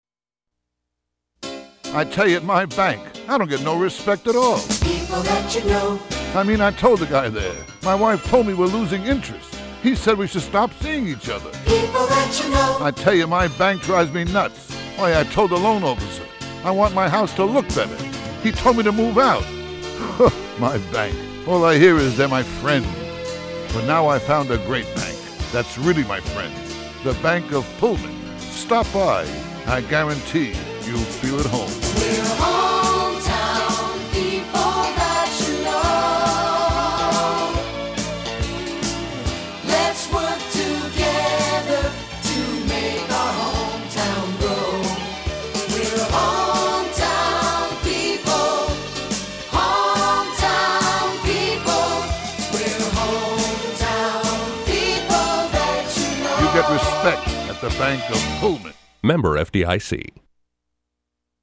Then, in 1993, something unexpected happened.